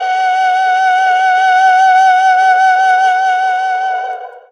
52-bi19-erhu-p-f#4.aif